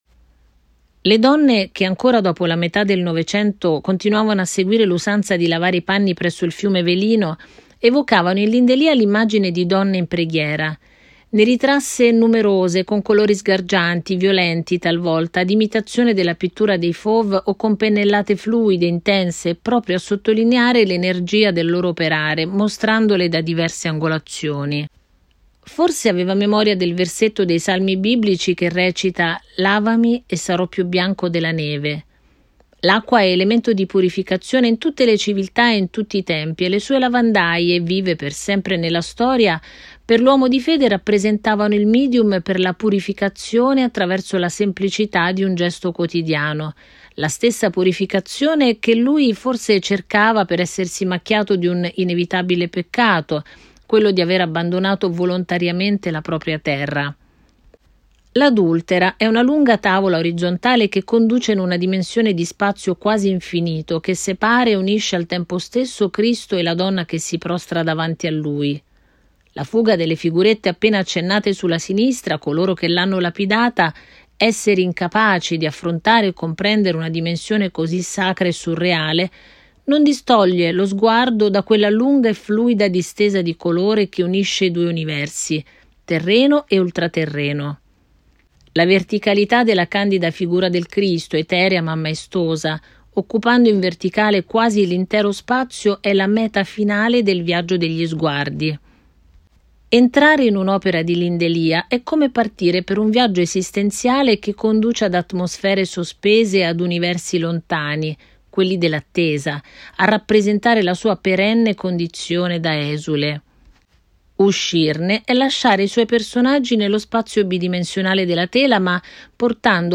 Testo Audioguide